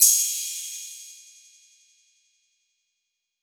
Crash 2.wav